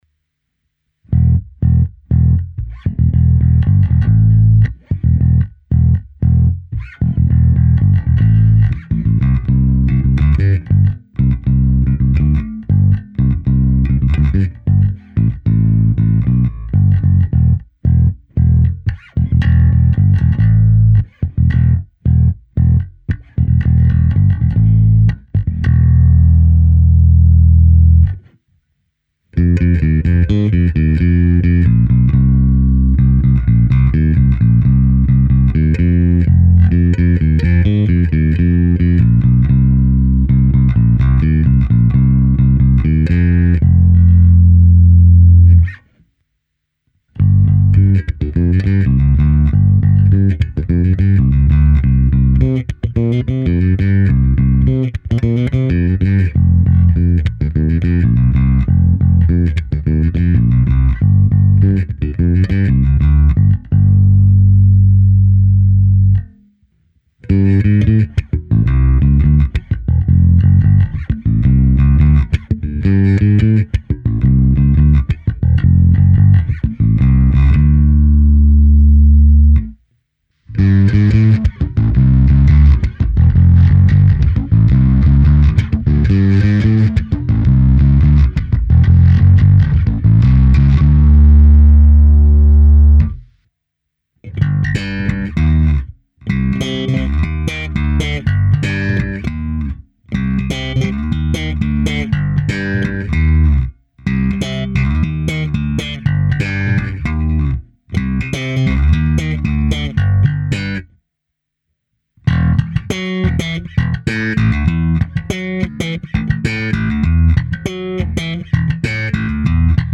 Nahrávka se simulací aparátu, kde bylo použito i zkreslení a hra slapem. Zde jsem použil takové nastavení, kdy jsem krkový snímač trochu stáhnul, tedy jsem upřednostnil kobylkový. Tento zvuk se mi zamlouvá nejvíce.